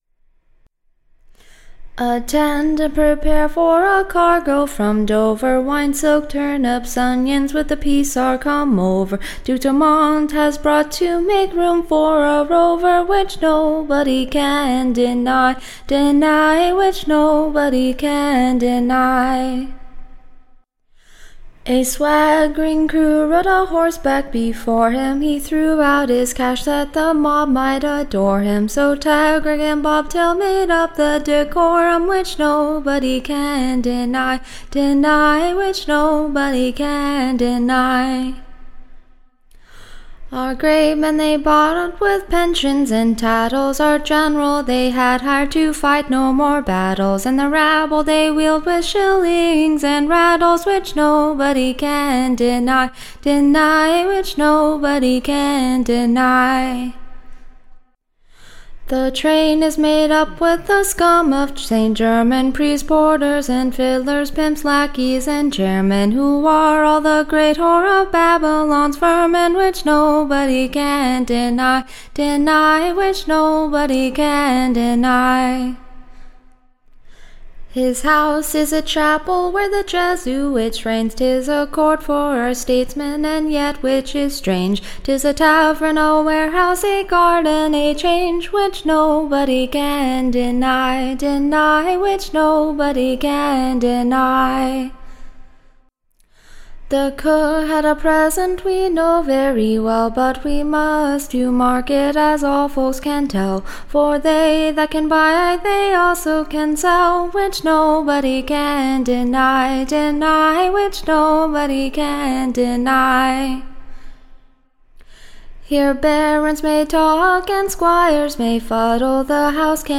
Recording Information Ballad Title THE / MERCHANT, / A-LA-MODE. Tune Imprint To the Tune of Which no body can deny. Standard Tune Title Greensleeves Media Listen 00 : 00 | 6 : 34 Download c1.145.mp3 (Right click, Save As)